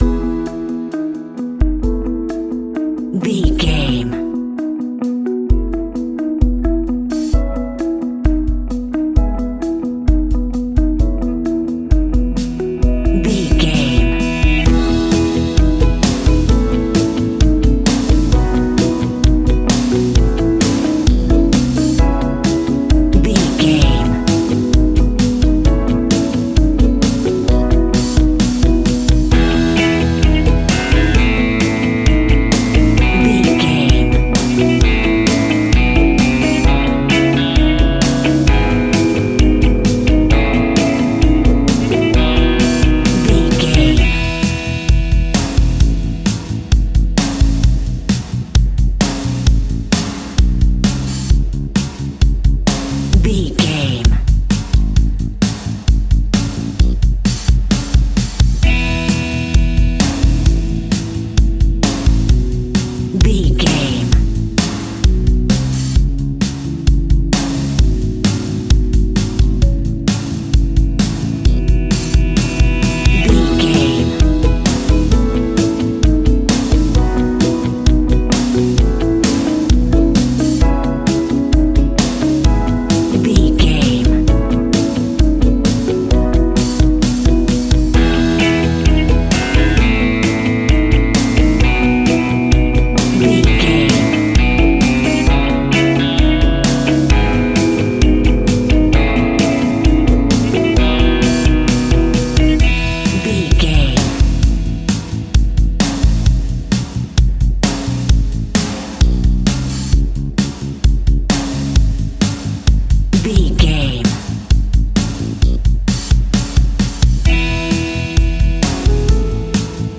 Uplifting
Ionian/Major
pop rock
indie pop
fun
energetic
guitars
bass
drums
piano
organ